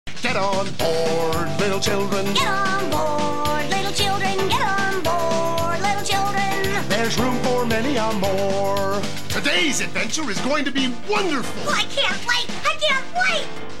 Nothing like some good old unintentional analog horror for your Thursday evening!